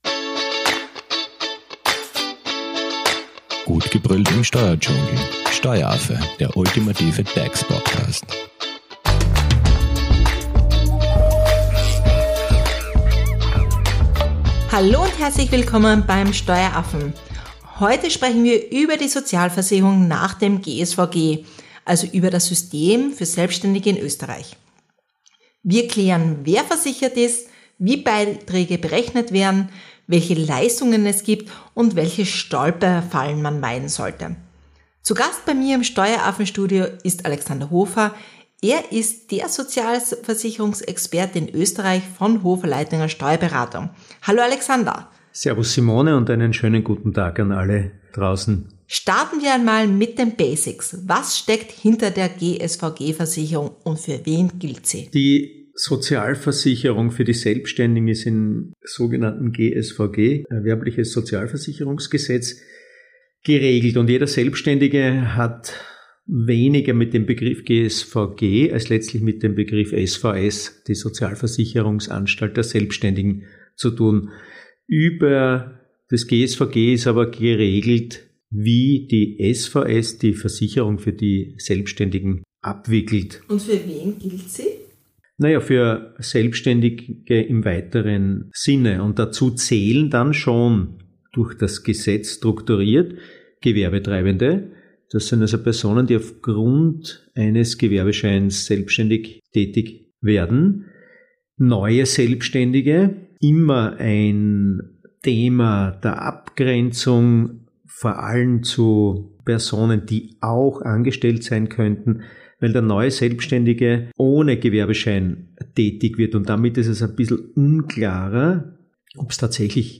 Zu Gast im Steueraffen Studio